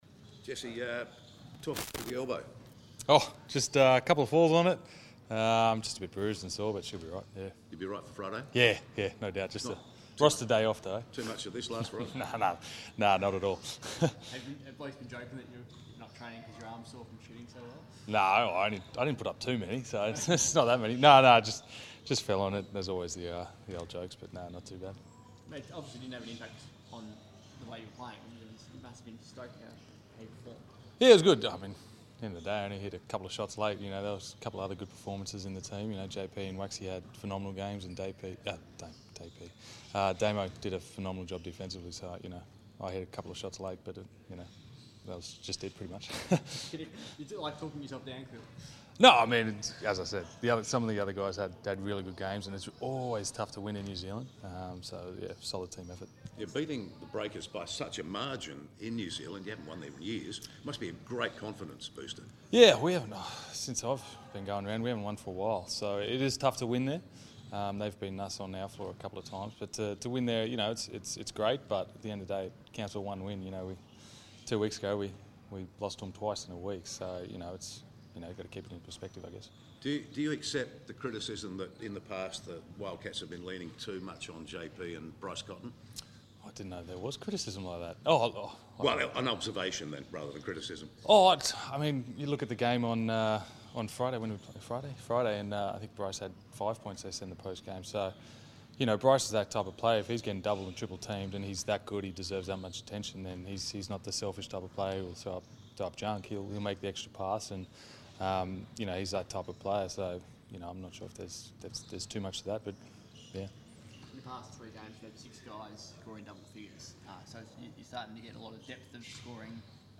Jesse Wagstaff Press Conference 4 December 2017